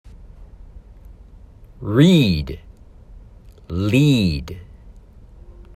参考：「read」と「lead」の発音
Read-Lead.m4a